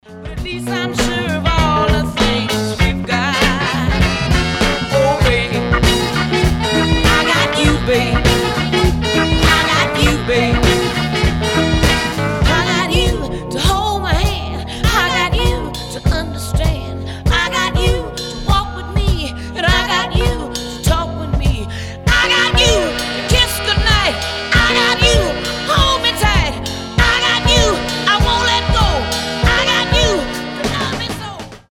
RnB
60-е
соул